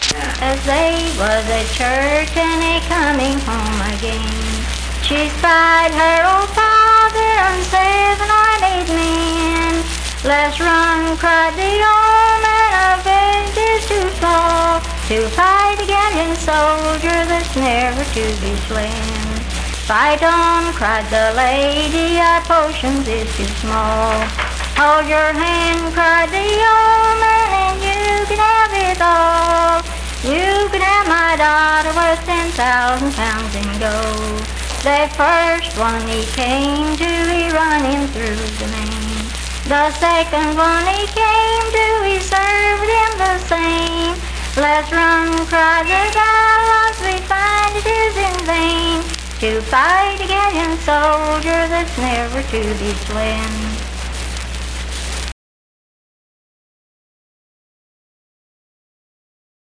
Unaccompanied vocal music
Voice (sung)
Roane County (W. Va.), Spencer (W. Va.)